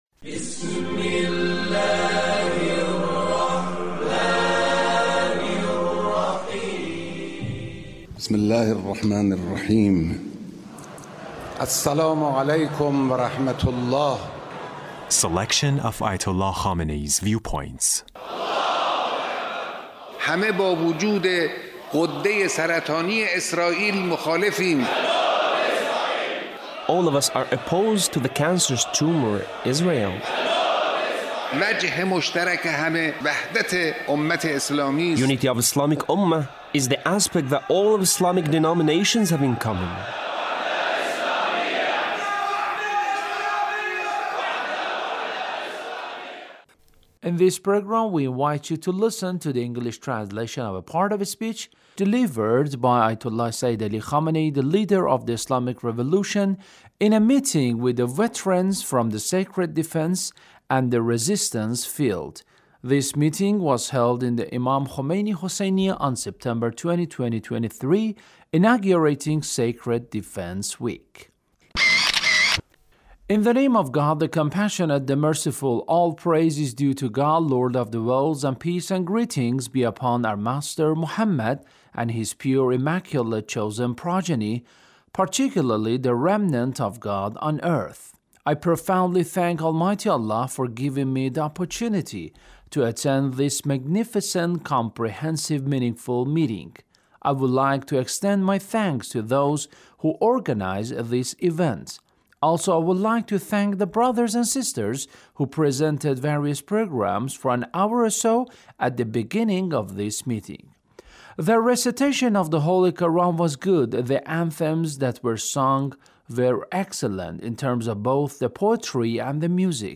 Leader's Speech (1844)
Leader's Speech on Sacred Defense